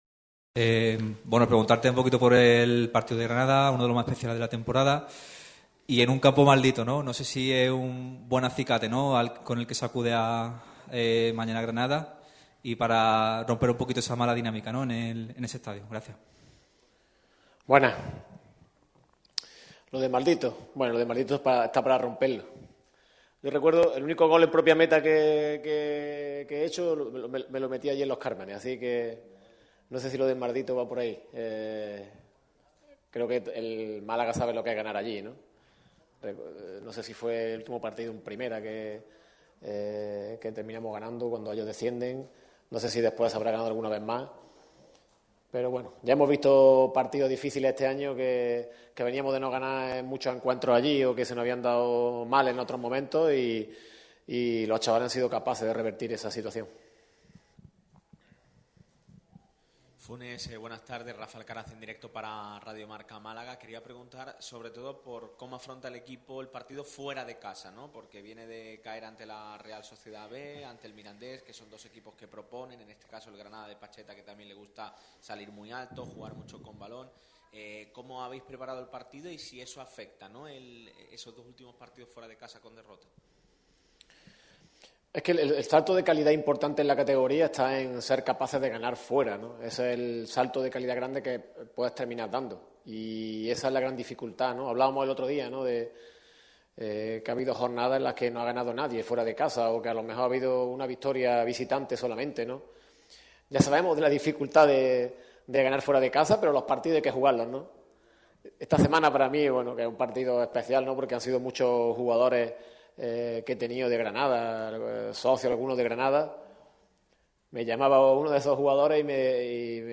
Rueda de prensa íntegra